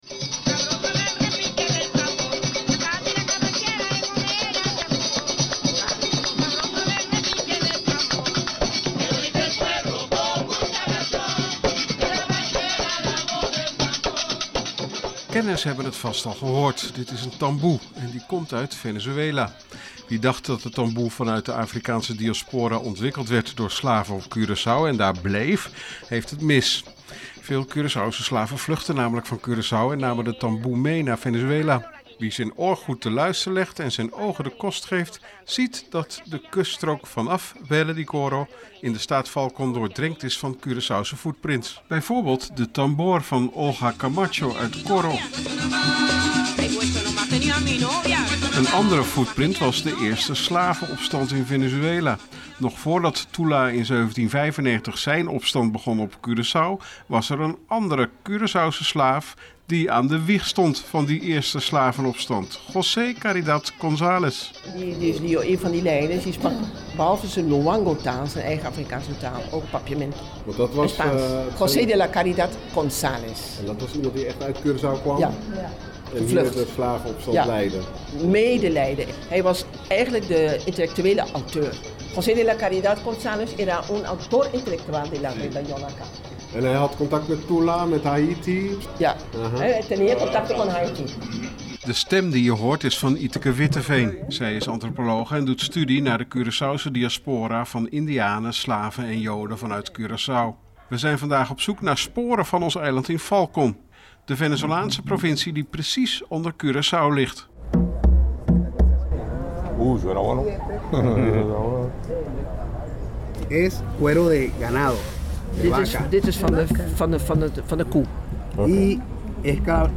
in Coro, Venezuela